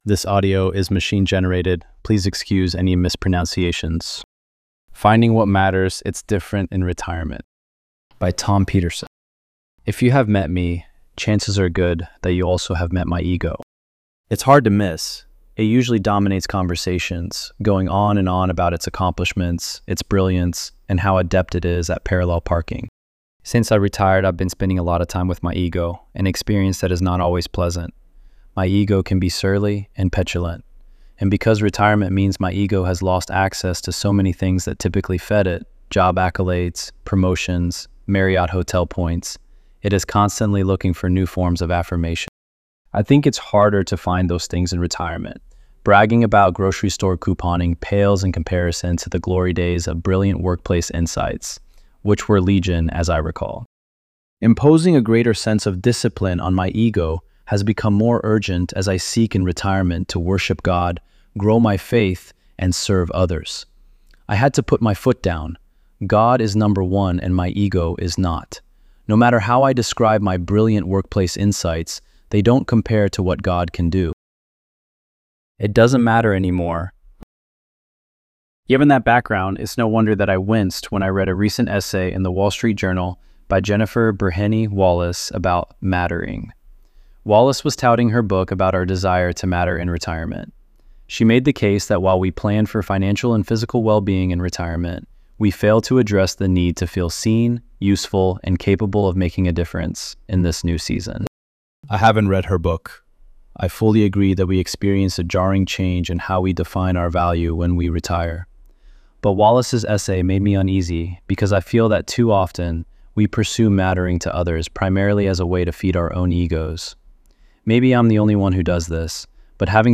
ElevenLabs_3_4.mp3